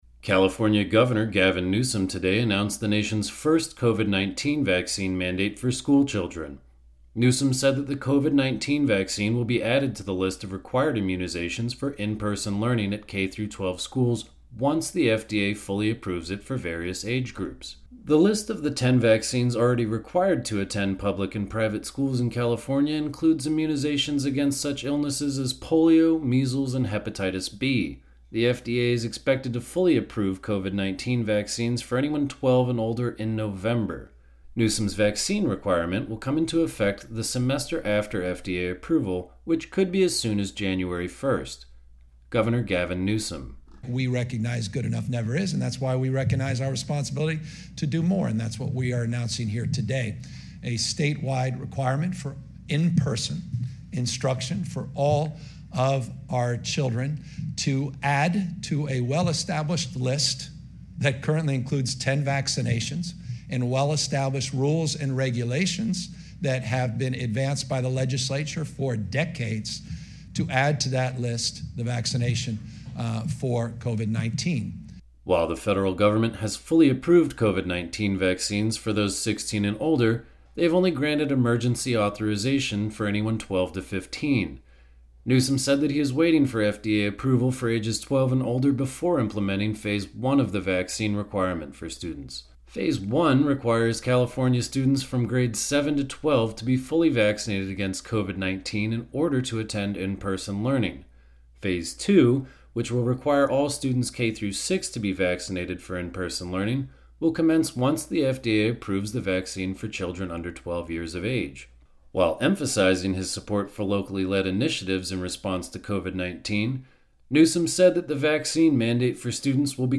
Comprehensive coverage of the day’s news with a focus on war and peace; social, environmental and economic justice.